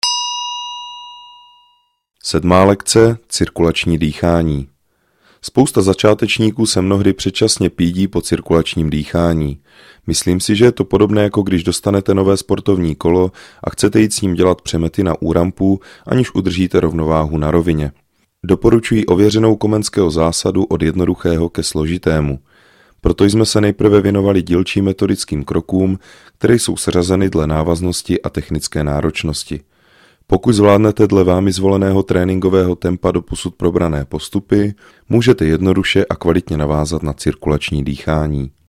VÝUKA HRY NA DIDGERIDOO I.
Didgeridoo je nástroj, jehož hluboký tón dokáže ukotvit pozornost v přítomném okamžiku.
Track 29 - 7 lekce - Cirkulacni dychani.mp3